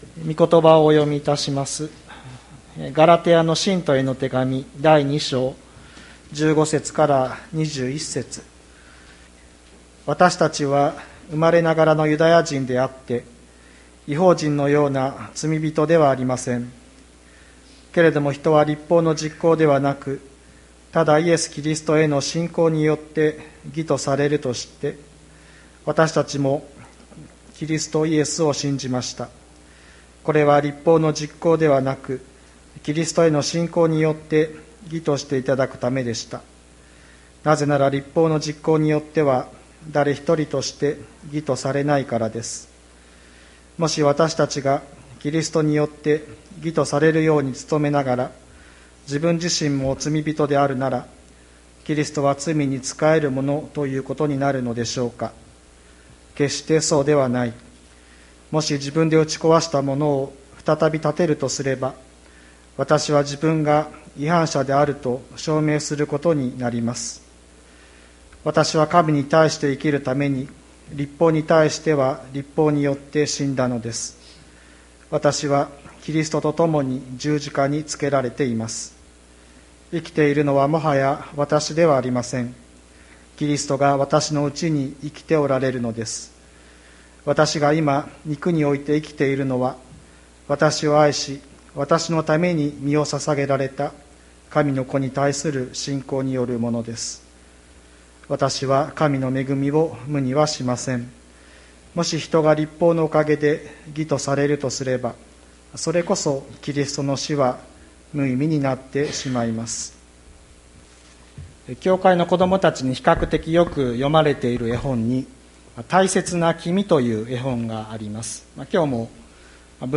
2020年10月25日朝の礼拝「生きているのは、もはやわたしではなく」吹田市千里山のキリスト教会
千里山教会 2020年10月25日の礼拝メッセージ。